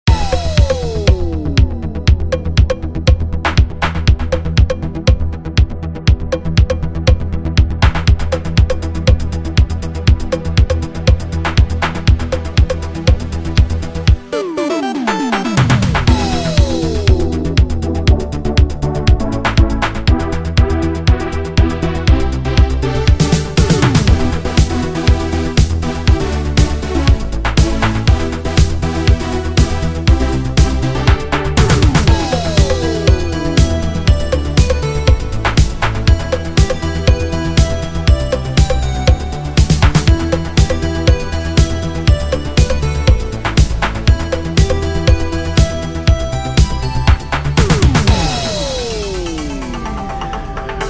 Tone2 Saurus 4是对经典模拟合成器的真实模拟，涵盖了模拟合成器的所有方面，但没有缺点。
然后，你会得到一个12分贝/24分贝的滤波器，带有超频和调频功能。它还带有合唱和混响效果。
• 类型： 合成器